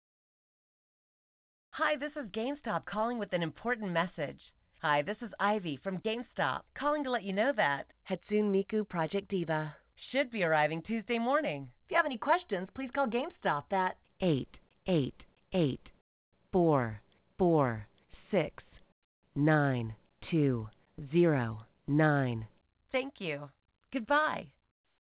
gamestop-project-diva-f-2nd-voice-message.wav